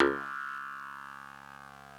genesis_bass_025.wav